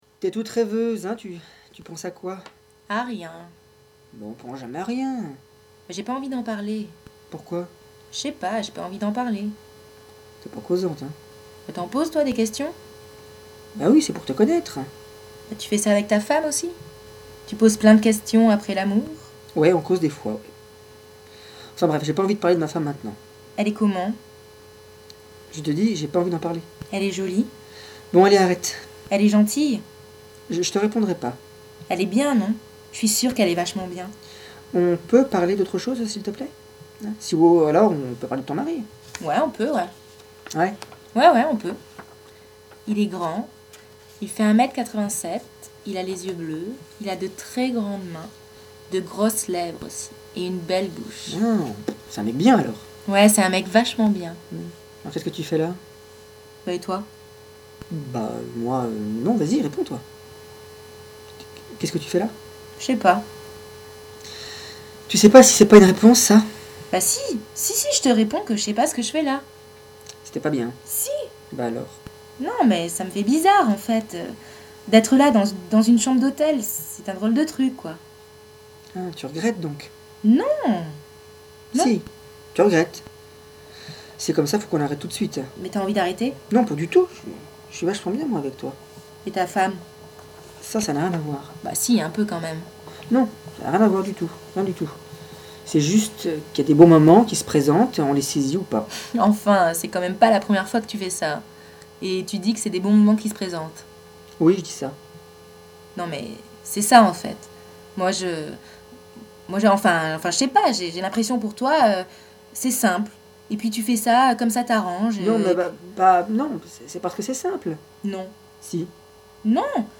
duo de voix H/F